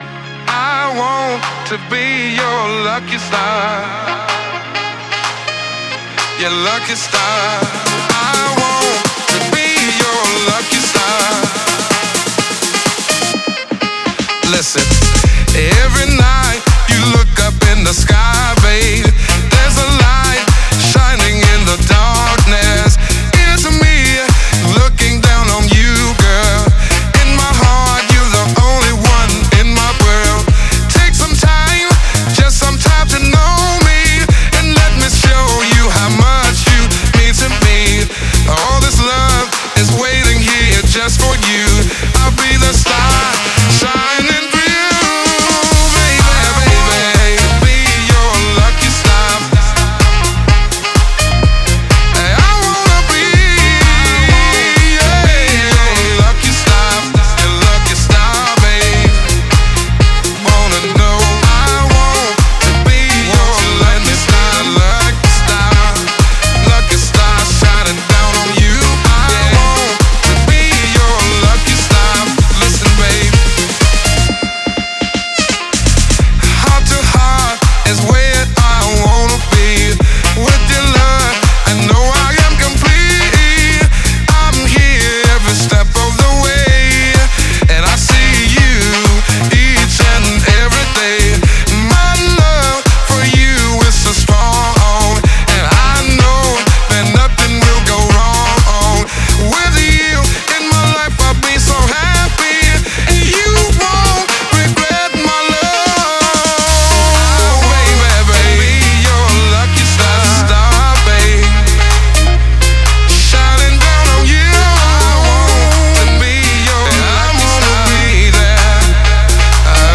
sax-infused twist.
Genre: House